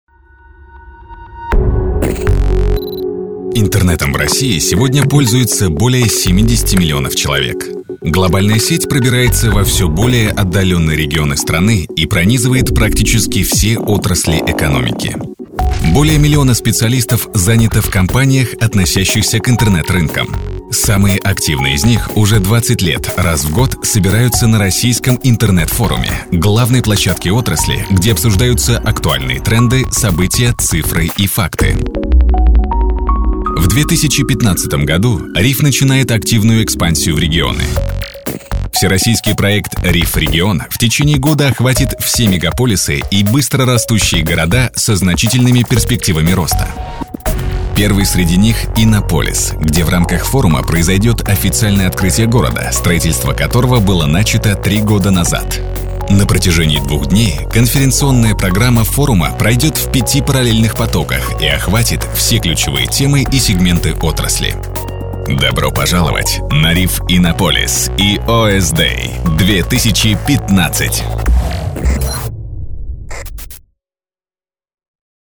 Профессиональная студия. Мощный голос. Гибкие подача и тембр.
Тракт: Изолированная дикторская кабина. Микрофон: RODE NTK.
Демо-запись №1 Скачать